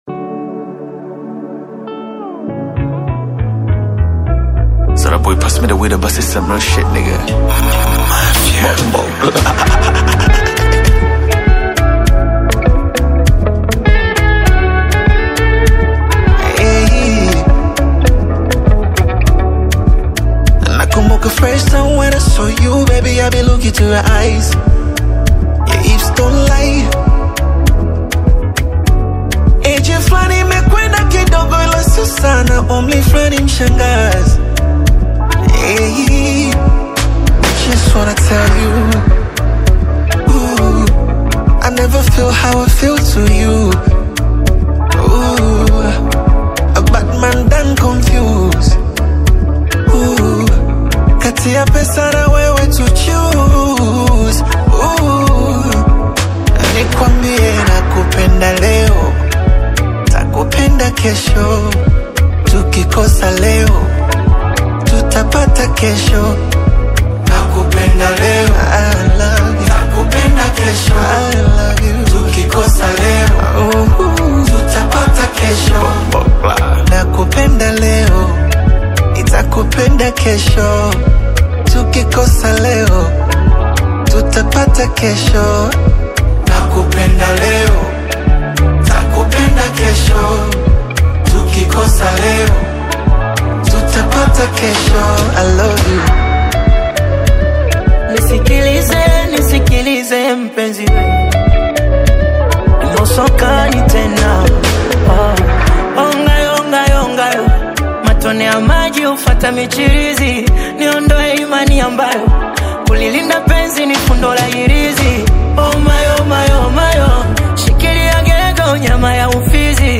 Popular Tanzanian urban music